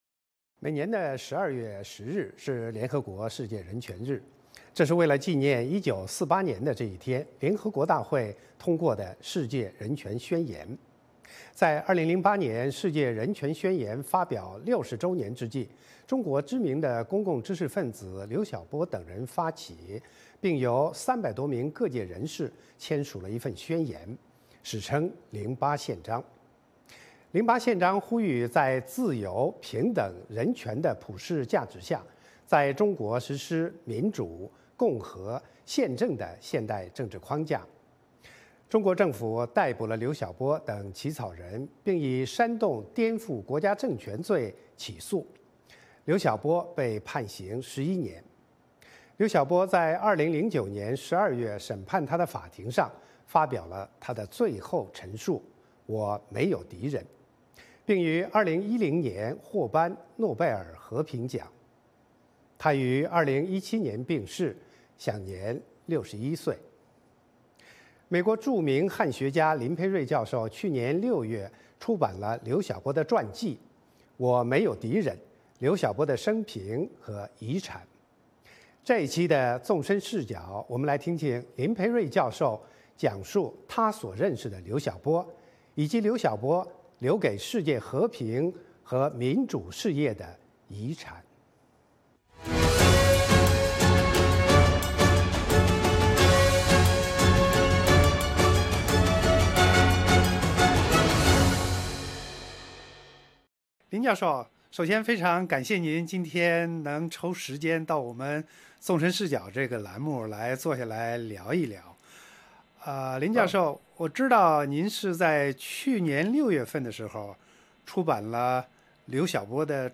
专访林培瑞：我所认识的刘晓波
林培瑞说，尽管刘晓波今天在中国鲜为人知，但《零八宪章》和刘晓波的思想将会成为未来中国民主转型的蓝图。《纵深视角》节目进行一系列人物专访，受访者所发表的评论不代表美国之音的立场。